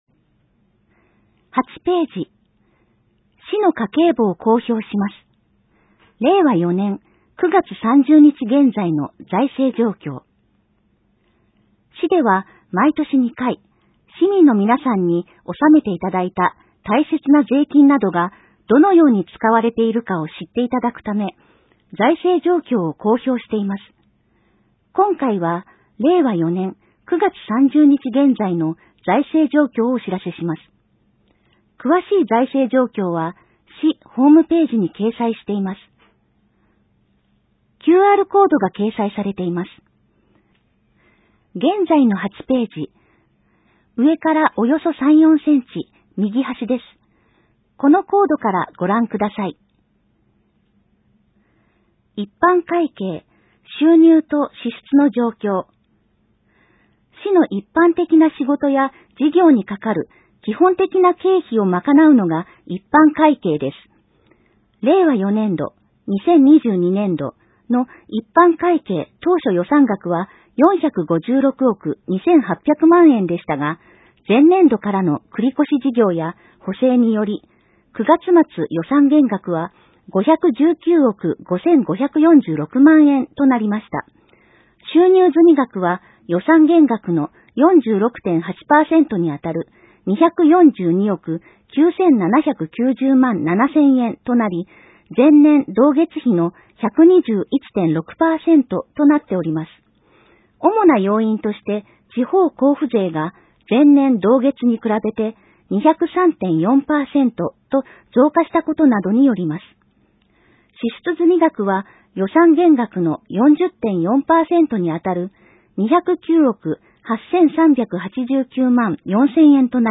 埼玉県朝霞市が発行している広報あさか１２月号を、リーディングサークルさんの協力で、音声にしていただいたものです。